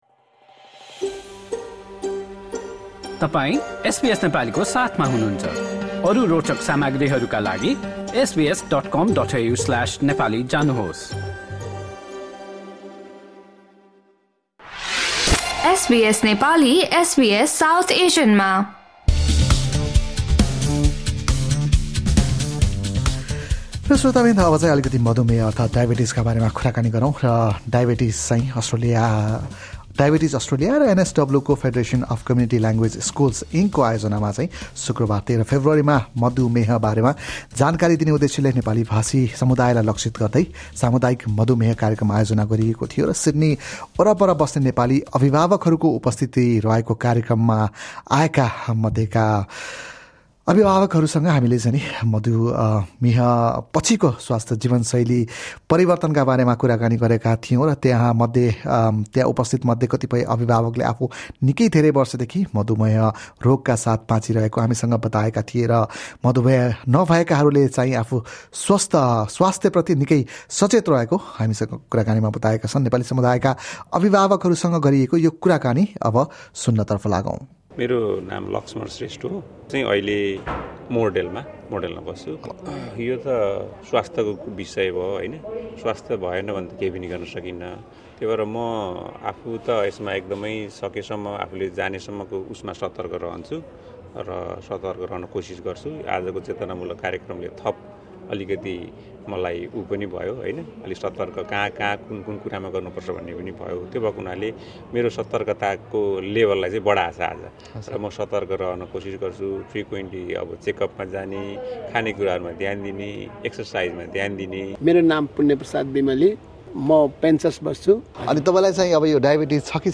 Diabetes Australia and the NSW Federation of Community Language Schools Inc organised a Community Diabetes Program on Friday, 13 February, targeting the Nepali community. SBS Nepali spoke with the workshop participants, especially visiting Nepali grandparents and an organising member, about diabetes awareness and experiences of living with the disease.